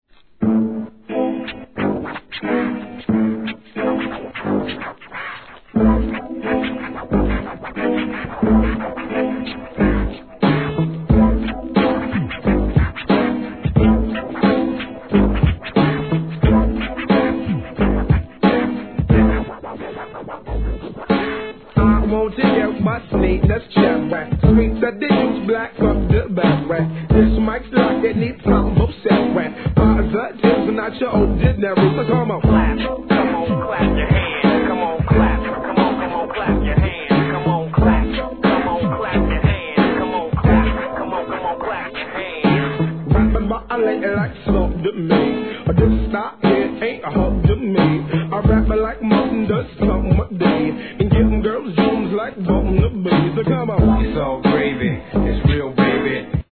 HIP HOP/R&B
ファットなキックにコスリがはまるフックもCOOL!!